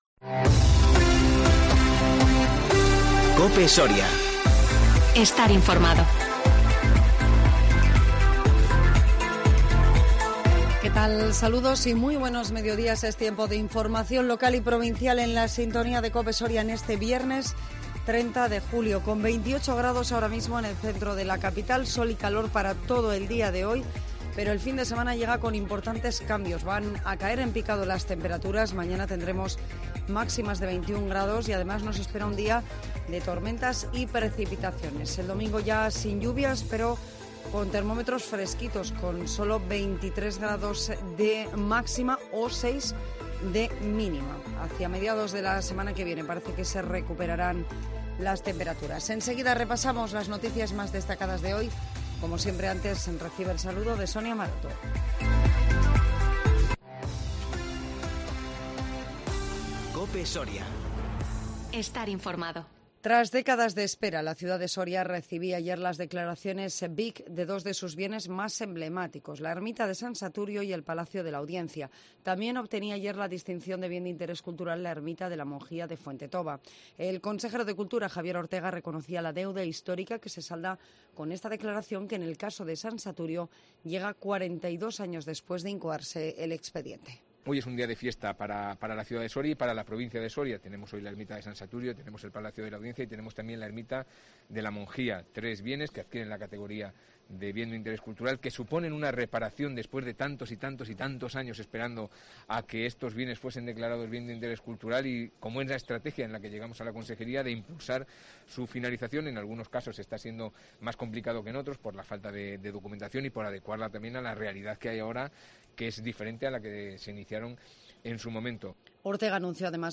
INFORMATIVO MEDIODÍA 30 JULIO 2021